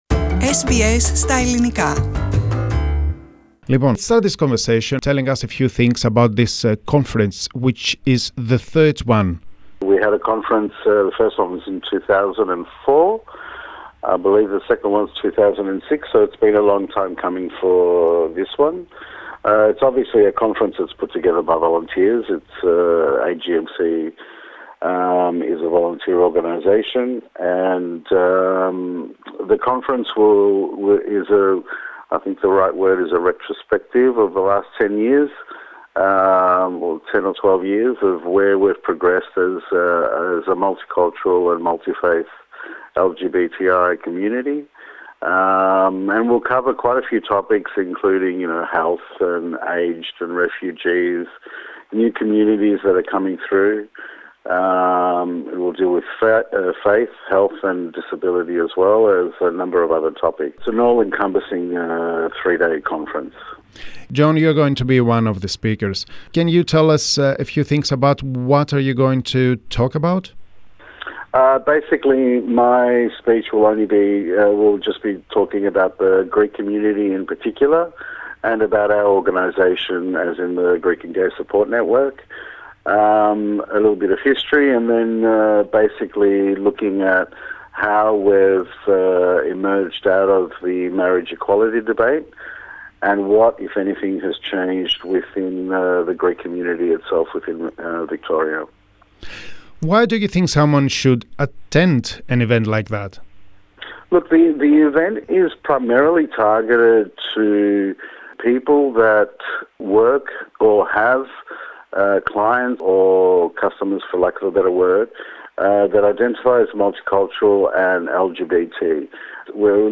Η συνέντευξη